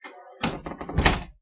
乌干达 " 门木质厚重的古董城堡地牢锁解锁转动钥匙打开关闭门闩回音 +bg声音
描述：门木重型古董城堡地牢锁解锁转钥匙打开关闭闩锁回声+ bg voices.wav
Tag: 开启 城堡 关闭 关键 木材 开锁 打开 地牢 古色古香